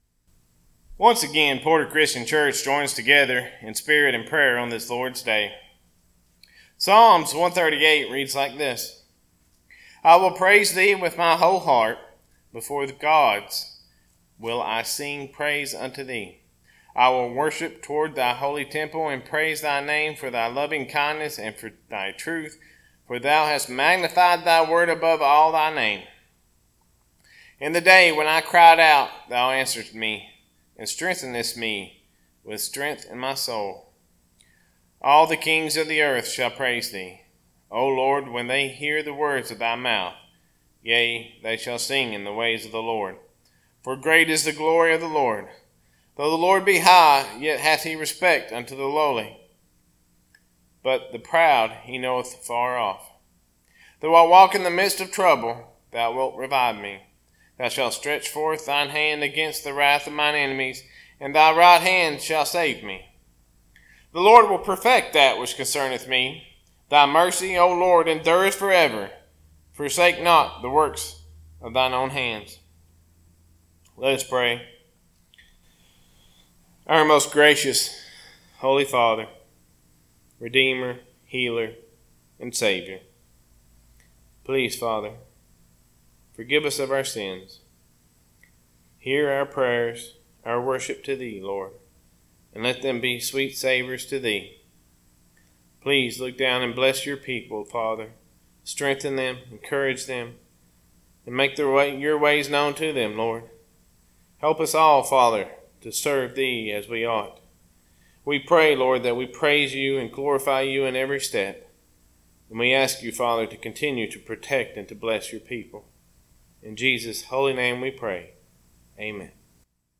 Sermons - Porter Christian Church